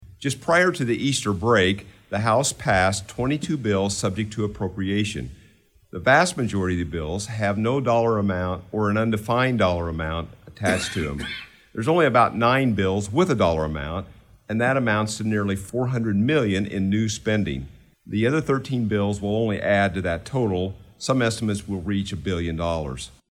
Shelbyville, IL-(Effingham Radio)- State Representative Brad Halbrook (R-Shelbyville) said today in a capitol press conference that one way the majority party is making the state of Illinois worse is through excessive spending.